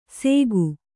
♪ sēgu